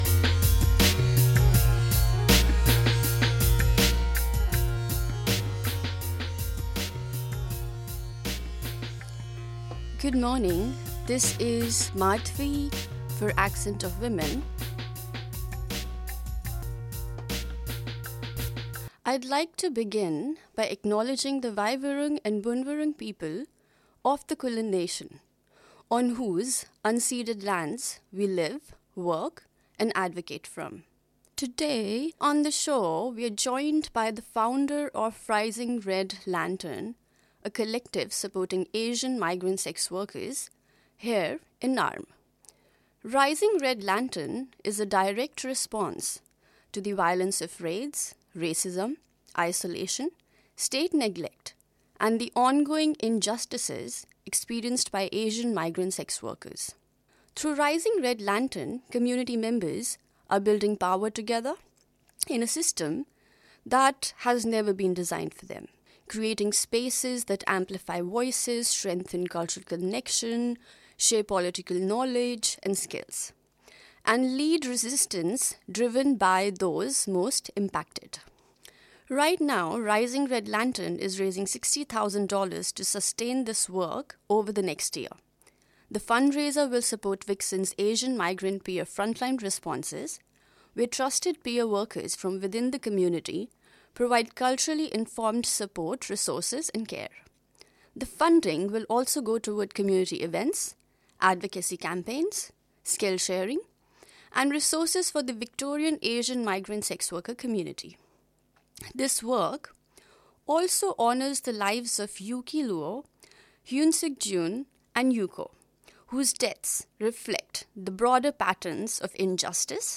Broadcast on the satellite service of the Community Broadcast Association of Australia the Community Radio Network Tuesdays 1.32PMA program by and about women from culturally and linguistically diverse backgrounds.